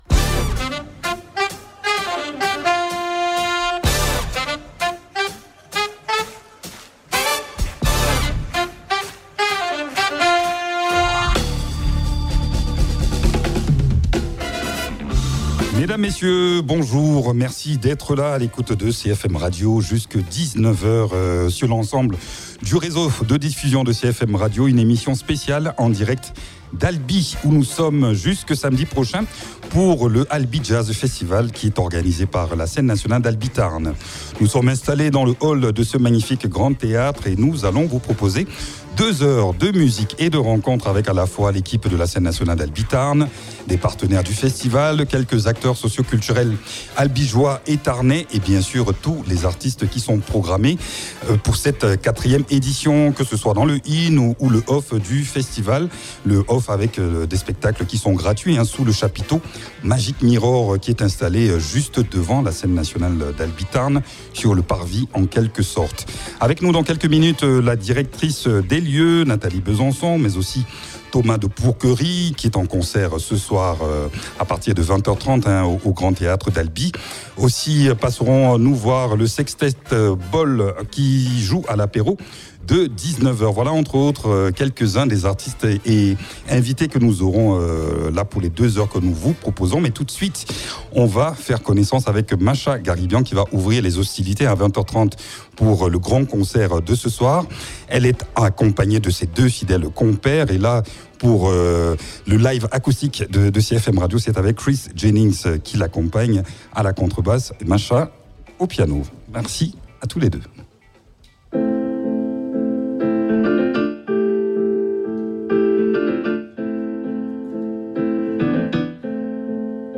batterie